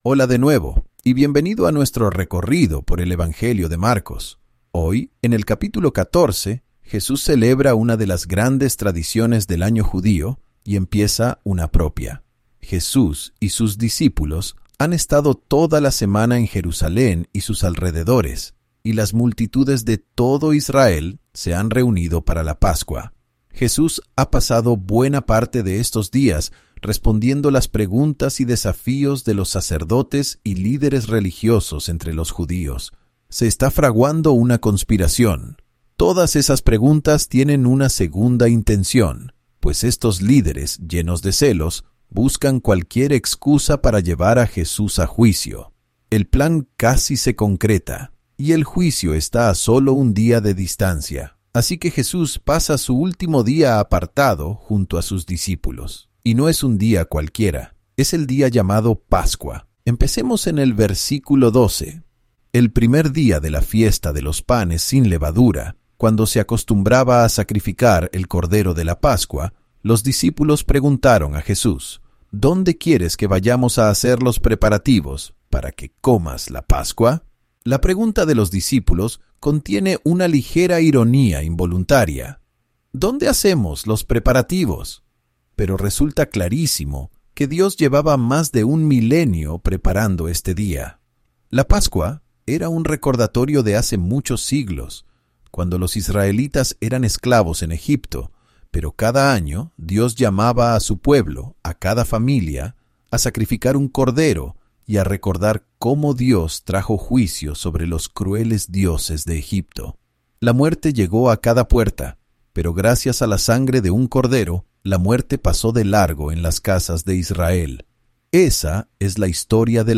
Cada capítulo aporta nuevas perspectivas y comprensión mientras tus maestros favoritos explican el texto y hacen que las historias cobren vida.